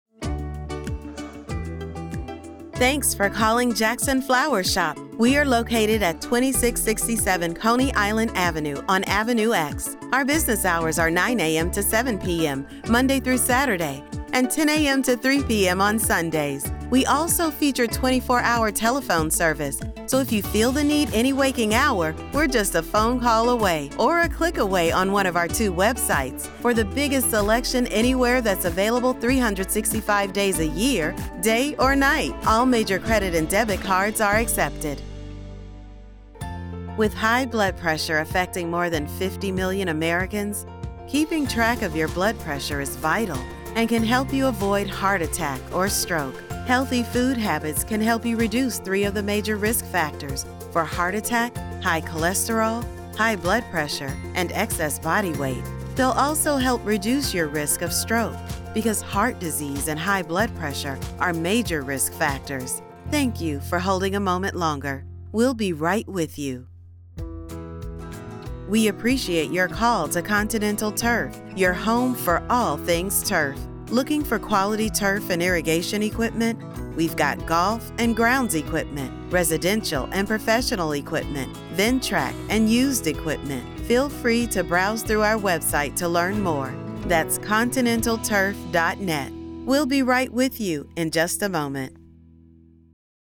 Has Own Studio
phone message